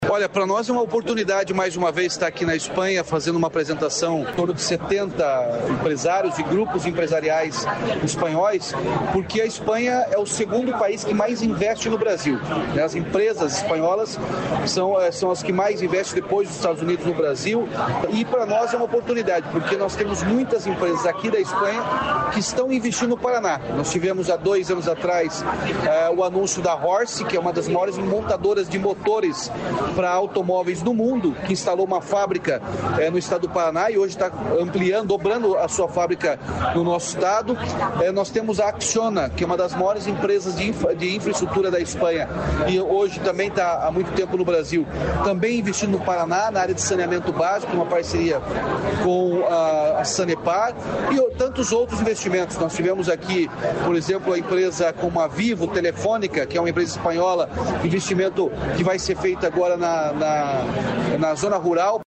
Sonora do governador Ratinho Junior sobre o Paraná Day em Madrid